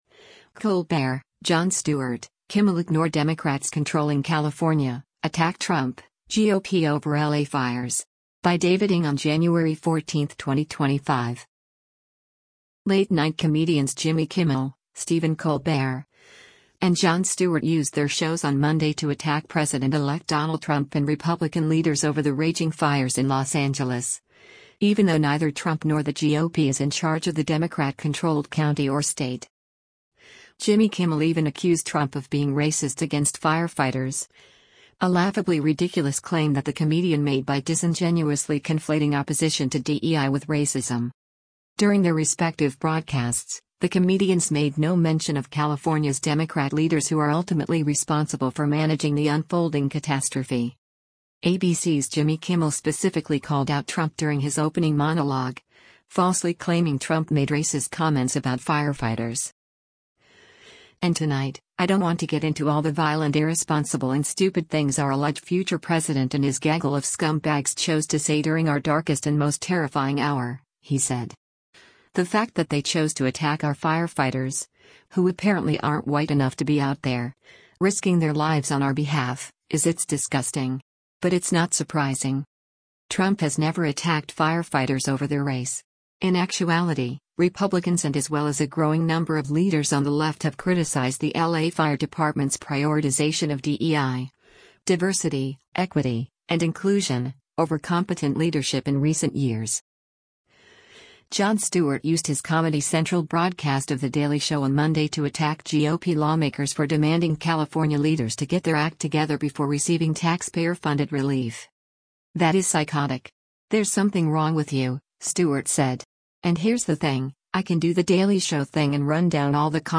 ABC’s Jimmy Kimmel specifically called out Trump during his opening monologue, falsely claiming Trump made racist comments about firefighters.
“You know who’s not helping? Congressional Republicans, because they want strings attached to California fire aid,” the comedian said Monday, eliciting boo’s from the studio audience.